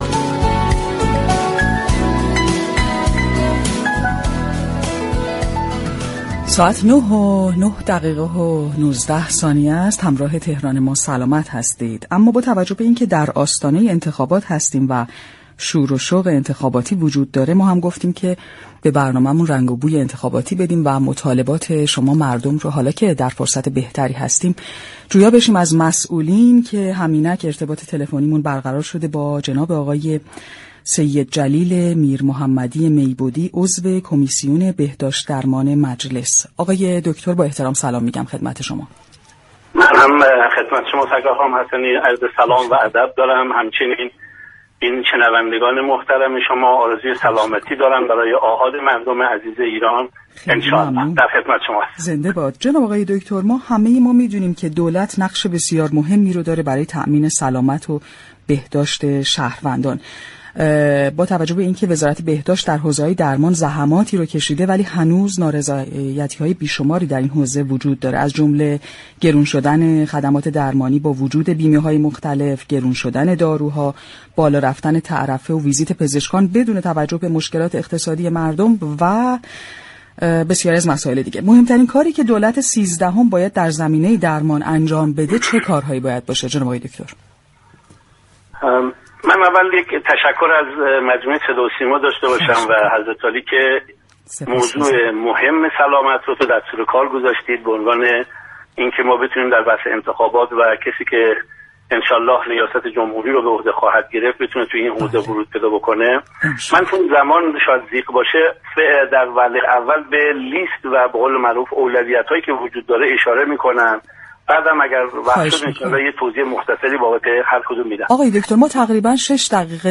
به گزارش پایگاه اطلاع رسانی رادیو تهران، سیدجلیل میرمحمدی میبدی عضو كمیسیون بهداشت و درمان مجلس یازدهم در گفتگو با برنامه «تهران ما سلامت» رادیو تهران مهمترین كارهایی كه دولت سیزدهم باید در راستای بهبود خدمات سلامت مردم انجام دهد اینچنین برشمرد: همه مردم انتظار دارند موضوع بهداشت و سلامت به عنوان اصلی ترین نیاز در اولویت كارهای رئیس جمهور باشد.